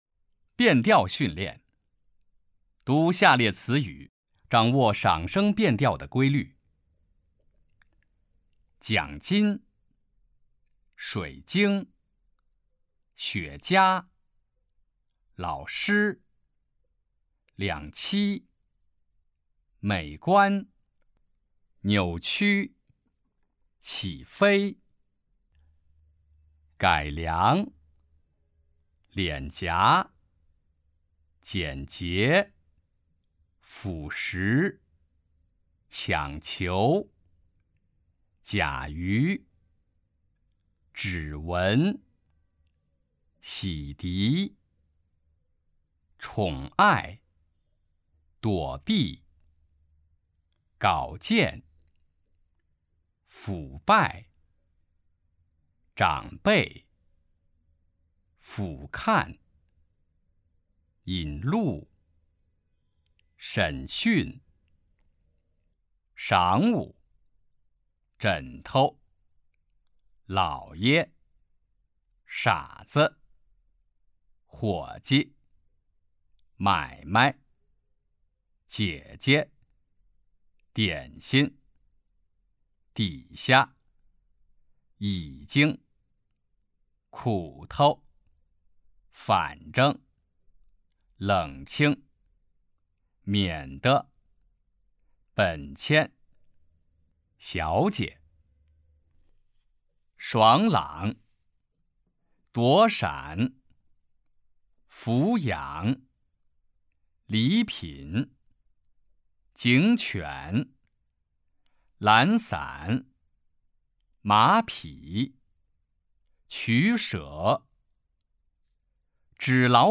普通话水平测试 > 普通话水平测试资料包 > 02-普通话水平测试提升指导及训练音频
008变调训练.mp3